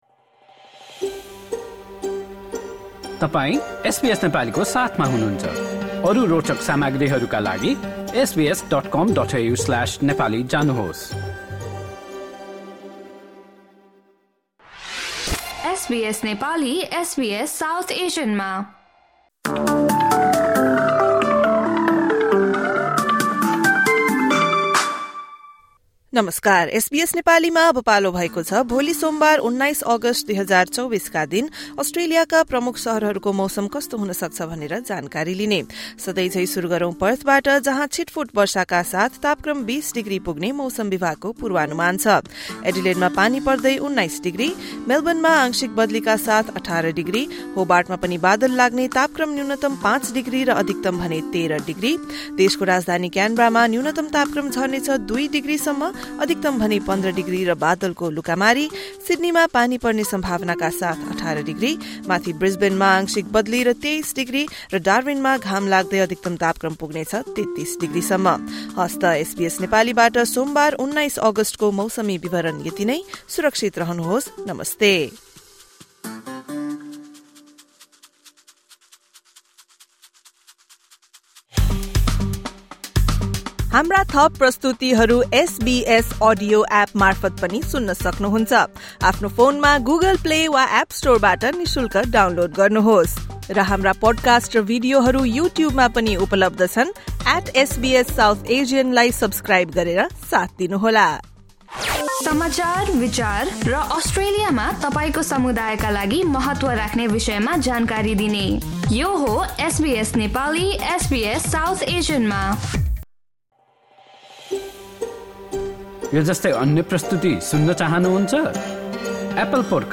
Australian weather update in Nepali for Monday, 19 August 2024.